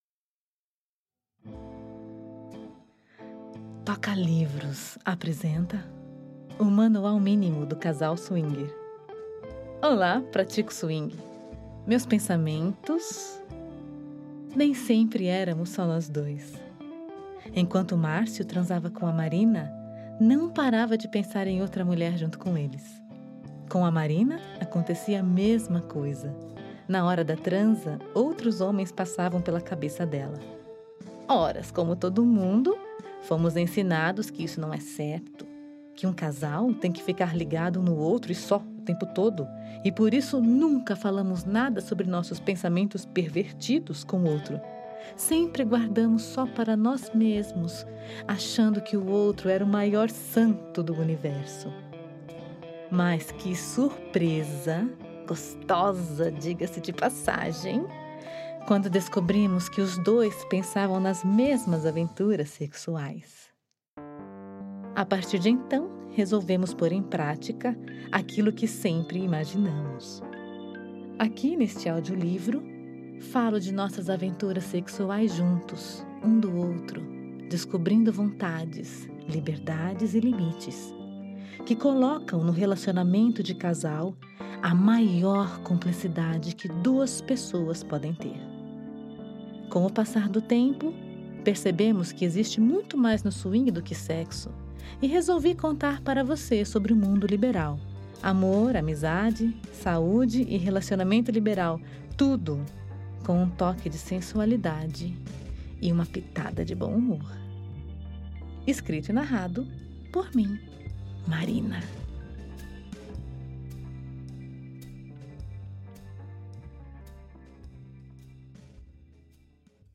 O Manual Mínimo do Casal Swing é um guia para os casais curiosos nesse estilo de vida (no swing, só pra ficar claro… rsrsrs) que querem entender melhor como funciona o mundo do swing e o melhor: o tempo não é um problema. Por ser um audiolivro, você pode ouvir em qualquer lugar!